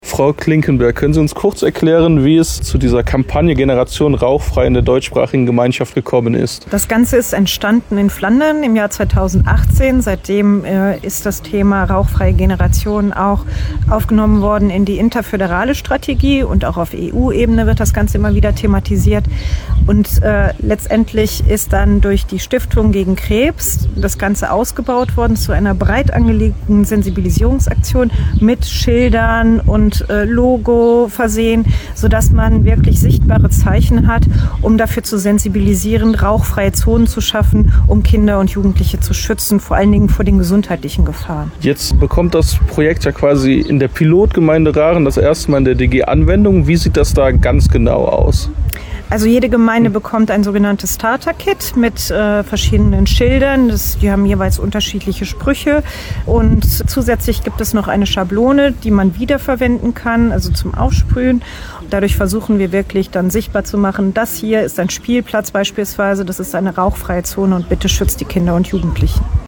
hat mit der zuständigen Ministerin Lydia Klinkenberg gesprochen.